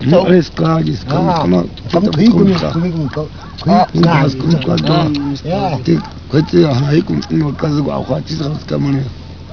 Die Sparache der San
hört sich so an.
sanklick.wav